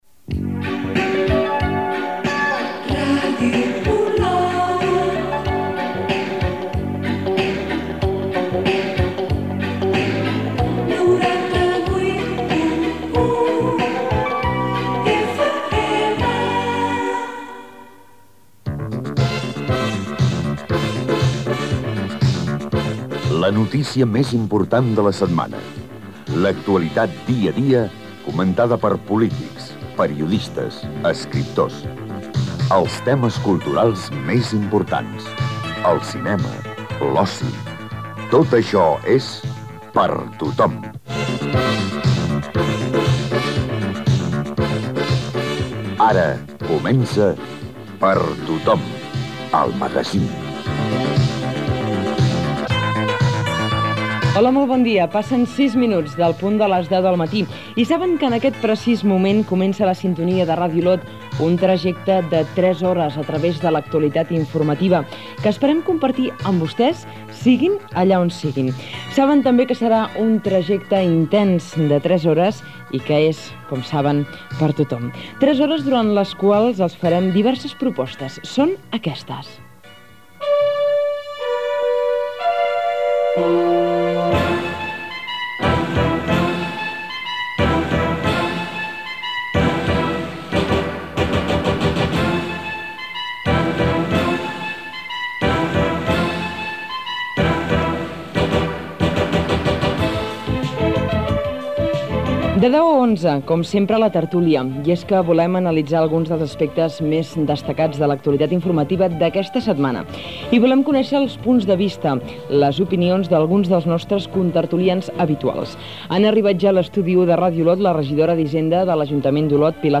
Indicatiu de l'emissora, careta del programa, careta, hora, presentació, sumari de continguts i invitats
Info-entreteniment